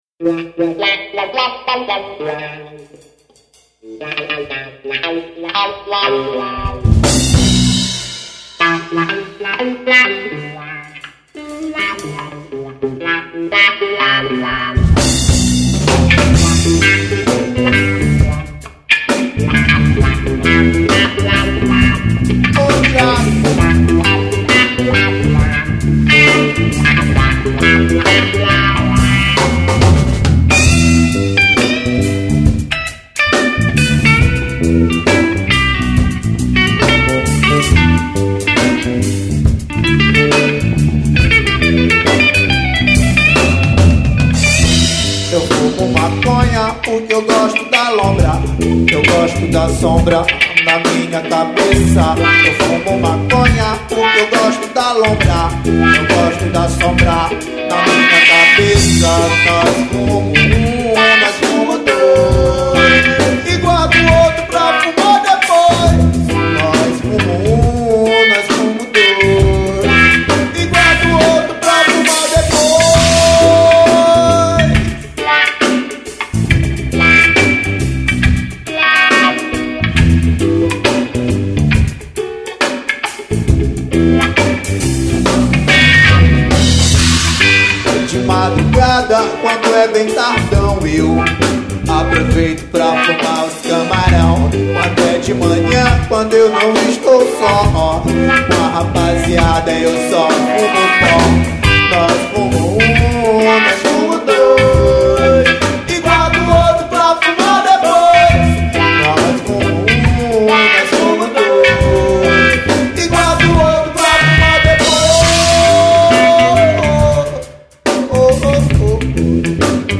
05:12:00   Reggae